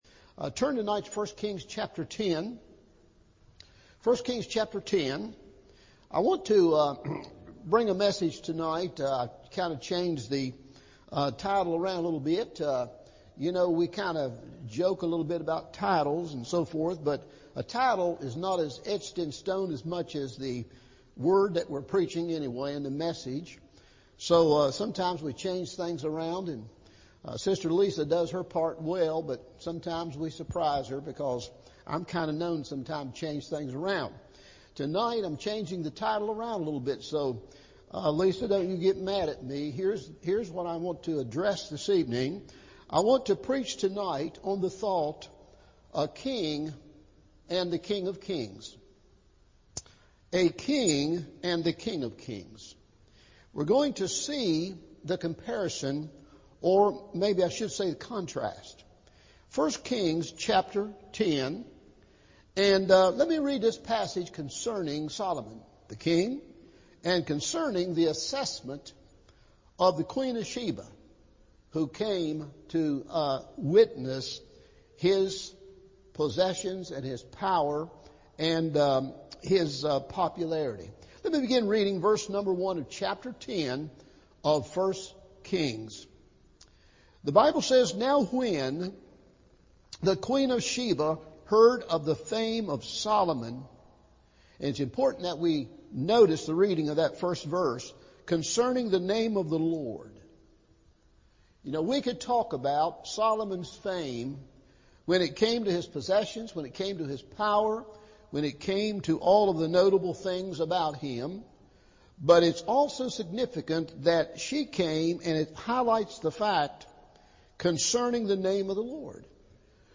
You Haven’t Heard The Half Of It! – Evening Service